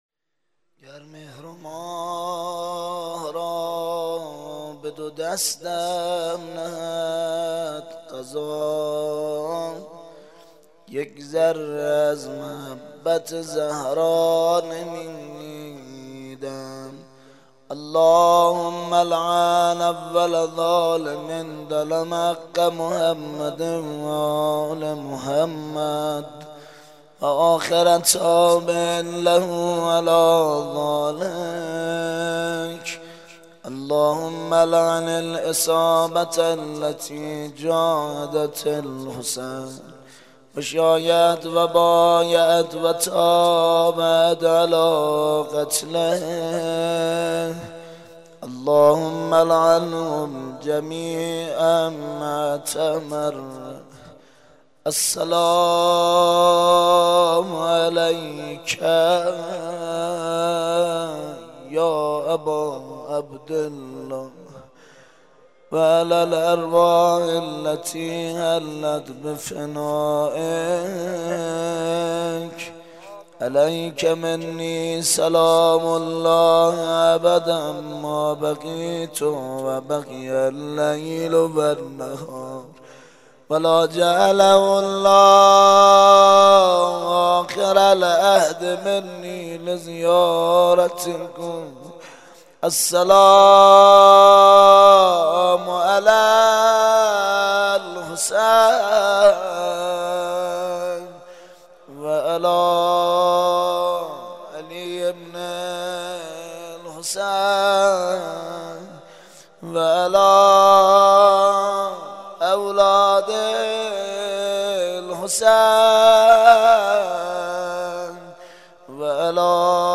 سینه زنی جهاد